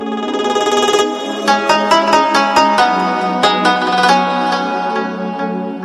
دانلود آهنگ پیامک 15 از افکت صوتی اشیاء
جلوه های صوتی
برچسب: دانلود آهنگ های افکت صوتی اشیاء دانلود آلبوم مجموعه جدید و زیبا برای زنگ اس ام اس - SMS Tones از افکت صوتی اشیاء